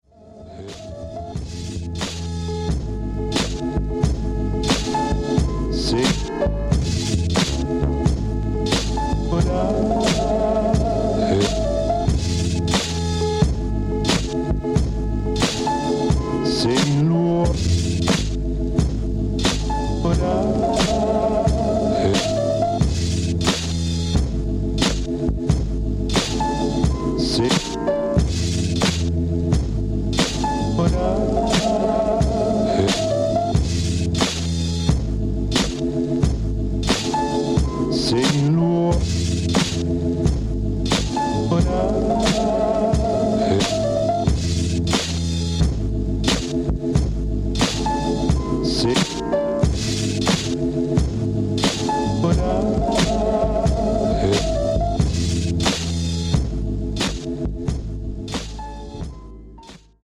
Killer Instrumental beats